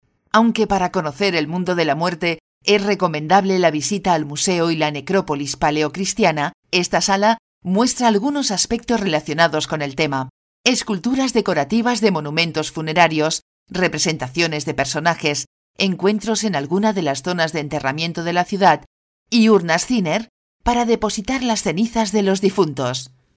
Bilingüe español-catalan; voz elegante media; mujer media; locutora española; locutora catalana; Spanish voiceover
Sprechprobe: eLearning (Muttersprache):